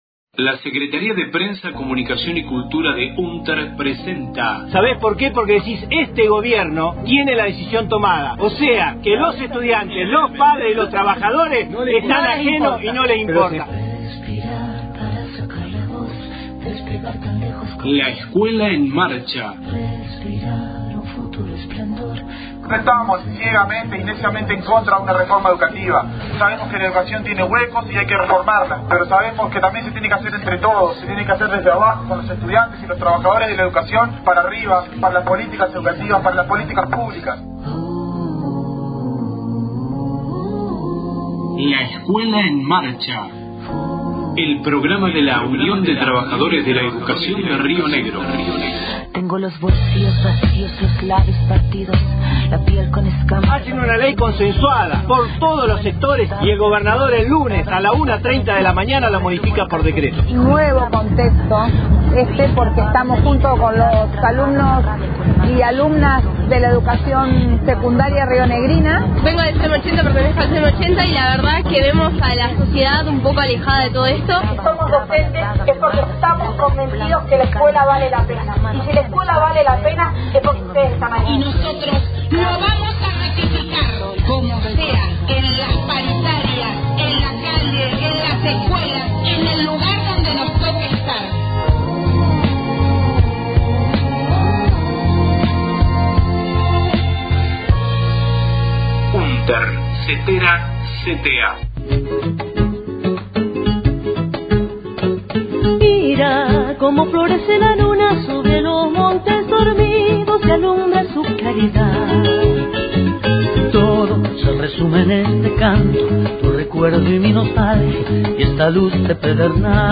LEEM radio 26/04/19 Audios de Conferencia de prensa 25/04/19 realizada por las CTA en la Seccional Roca – Fiske Menuco de UnTER para señalar acciones conjuntas en el marco del paro nacional del 30 de abril.